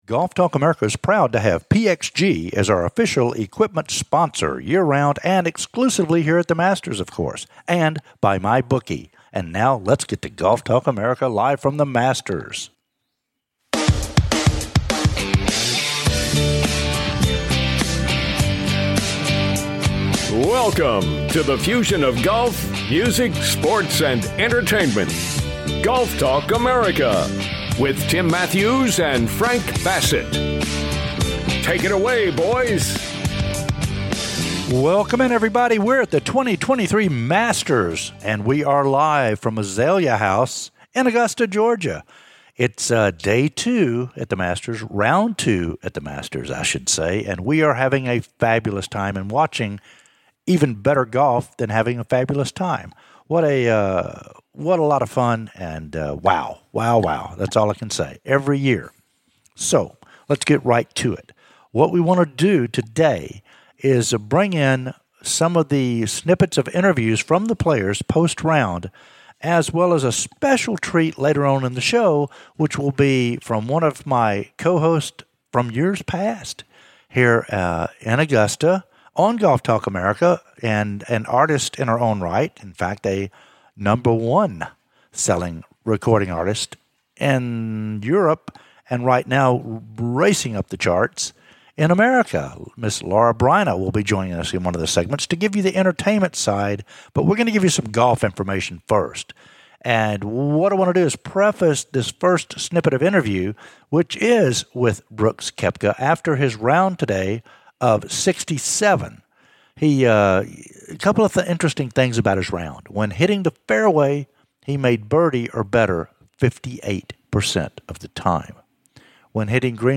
"LIVE" FROM DAY 2 OF THE 2023 MASTERS